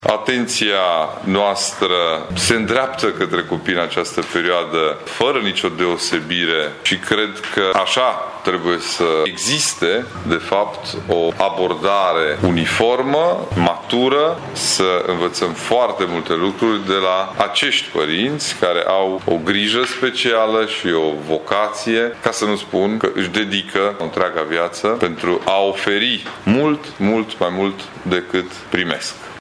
Programul manifestării a fost anunțat, astăzi, în cadrul unei conferințe de presă.
Competiția are loc în fiecare an în preajma zilei de 1 Iunie când trebuie readusă în discuție, mai mult ca oricând, tema familiei, spune președintele Consiliului Județean Mureș, Ciprian Dobre: